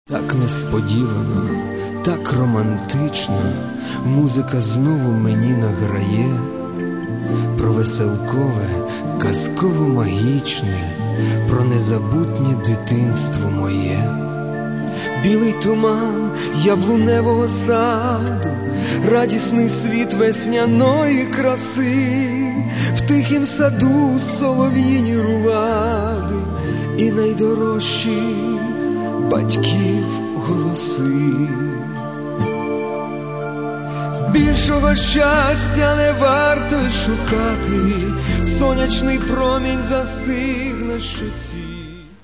Каталог -> Естрада -> Співаки